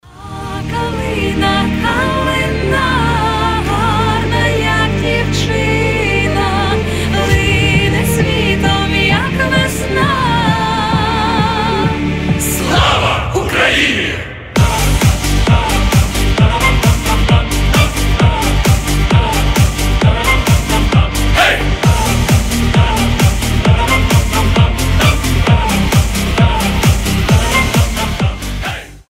украинские
Флейта
дудка